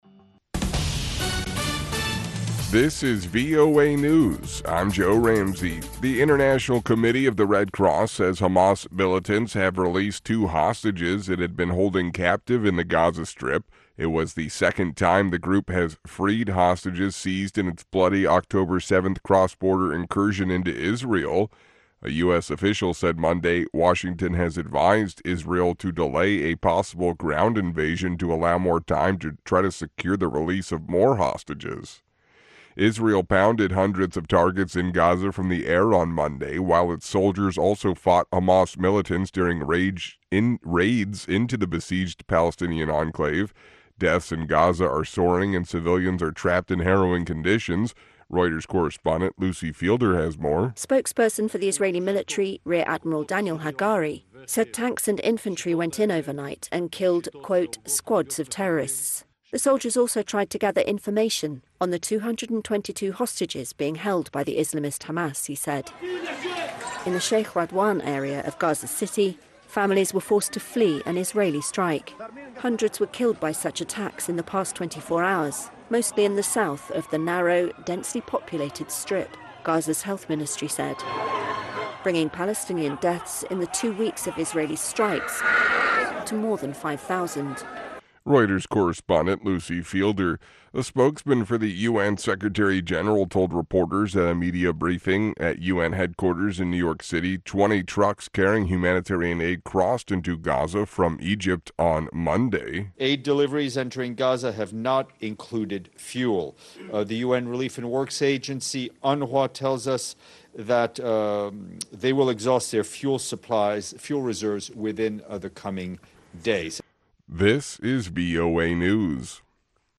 VOA 2-min news の音声はかなり速いので、プレイヤーの ボタンを押して、ゆっくりにしてシャドウイングを試してみても効果があります。